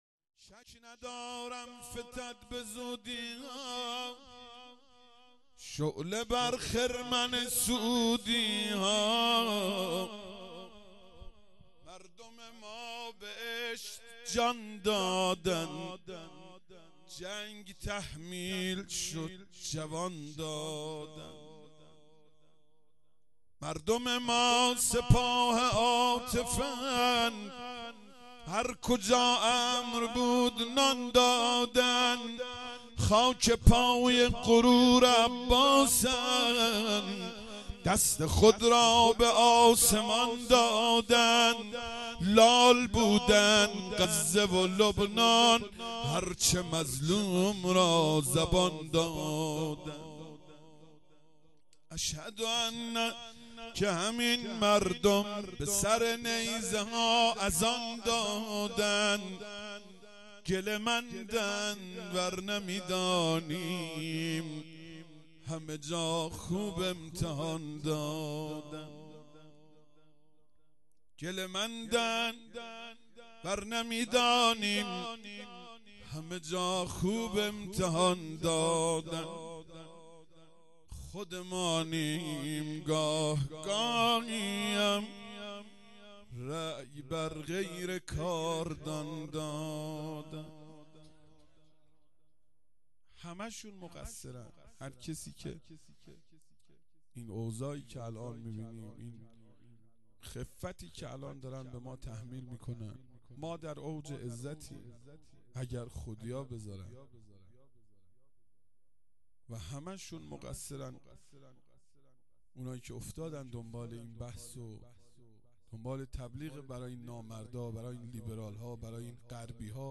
شعر پایانی
مراسم چهلم سردار قاسم سلیمانی